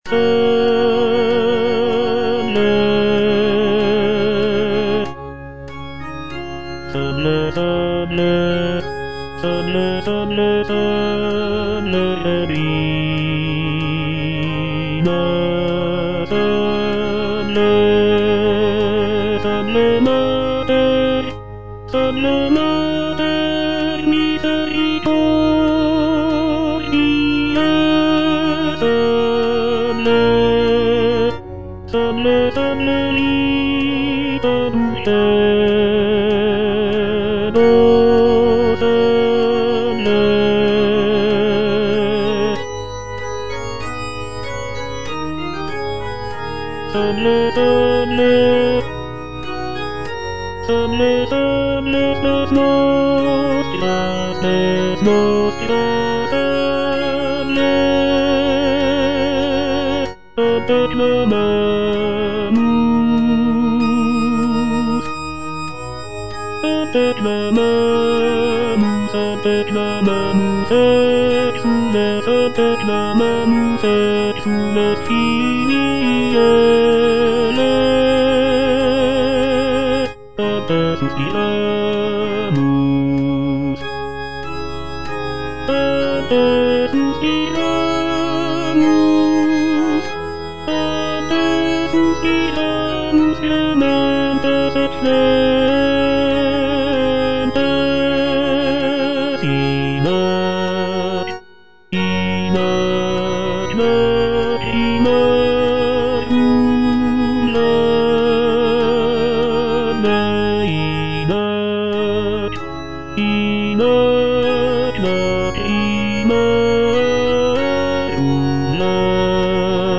G.F. SANCES - SALVE, REGINA (A = 415 Hz) Tenor (Voice with metronome) Ads stop: auto-stop
"Salve, Regina (A = 415 Hz)" is a sacred choral work composed by Giovanni Felice Sances in the 17th century.
The work features rich harmonies, expressive melodies, and intricate vocal lines, showcasing Sances' skill as a composer of sacred music.